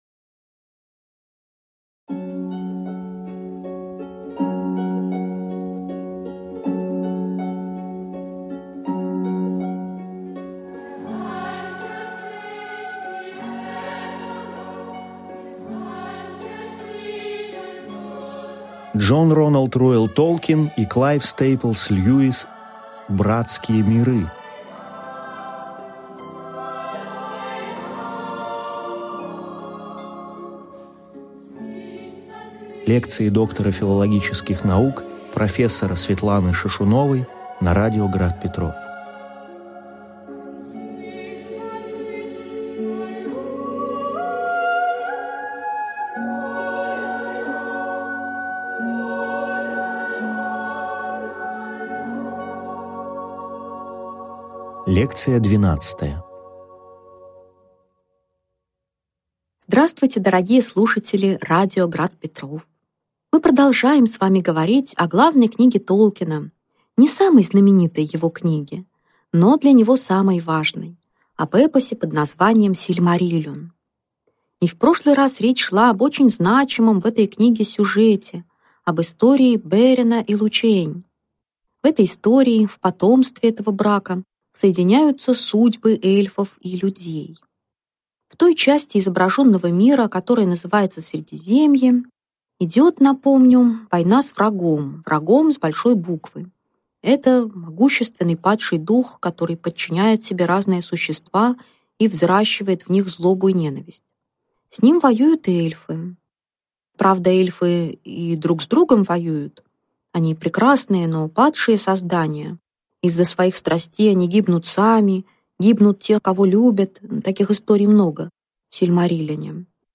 Аудиокнига Лекция 12. Дж.Р.Р.Толкин. «Сильмариллион»: судьба Средиземья | Библиотека аудиокниг